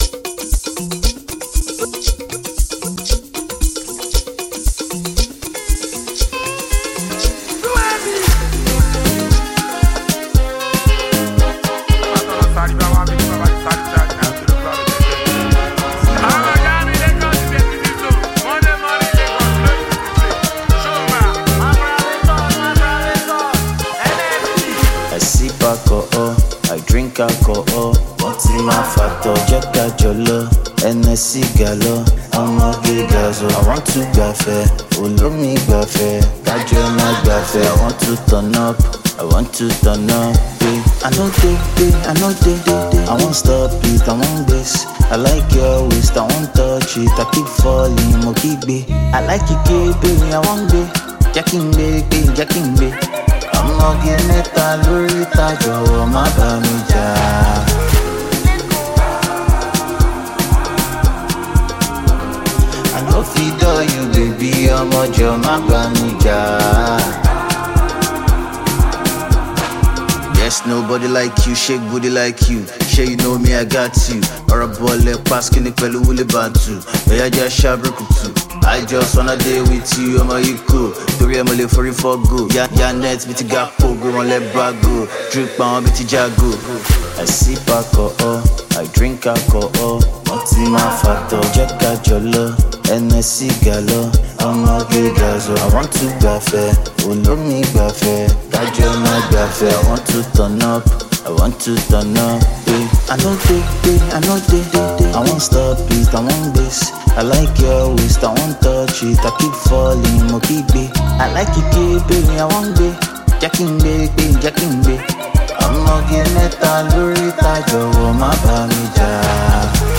Nigerian Afrobeats act